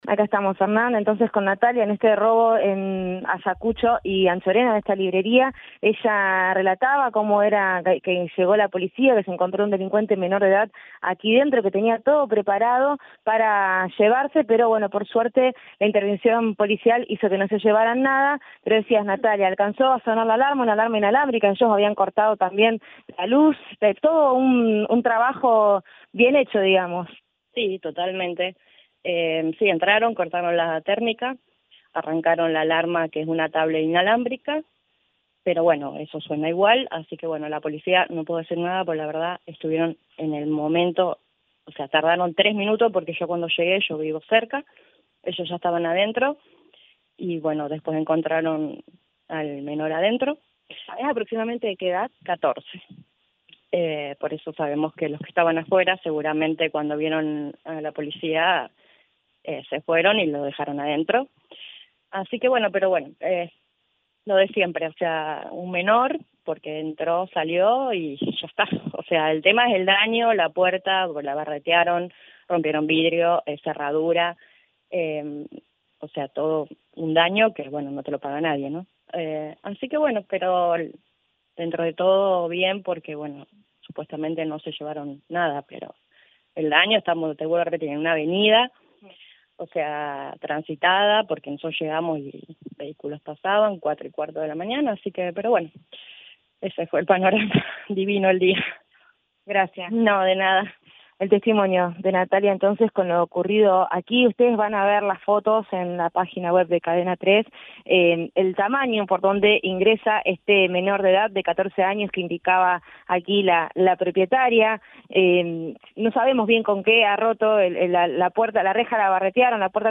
“Entraron, cortaron la térmica y arrancaron la alarma, pero sonó igual. La policía tardó tres minutos en llegar. El ladrón que encontraron tiene 14 años. el tema es el daño, rompieron vidrios y cerraduras. Es un daño que no te lo paga nadie”, lamentó en diálogo con el móvil de Cadena 3 Rosario, en Radioinforme 3.